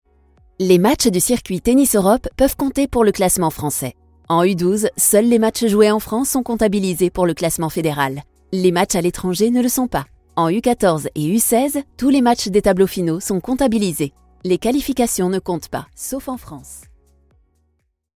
Tief, Verspielt, Vielseitig, Sanft, Corporate
Erklärvideo